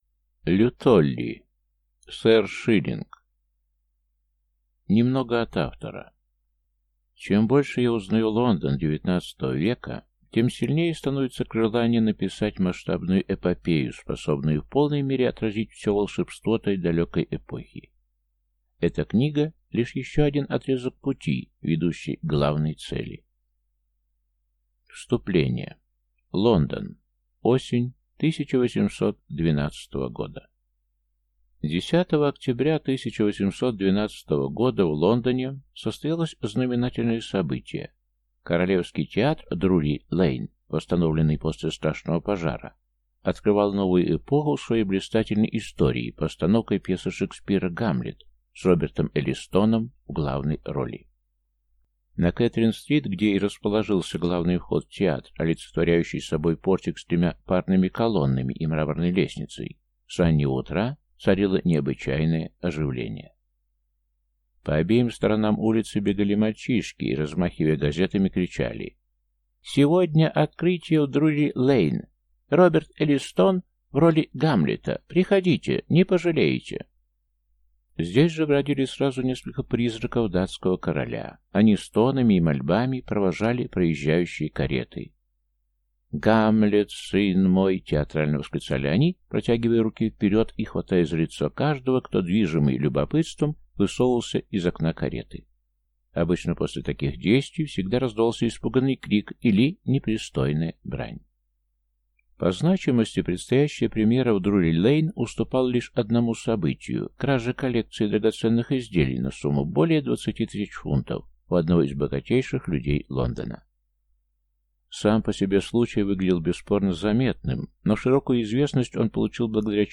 Аудиокнига Сэр Шиллинг | Библиотека аудиокниг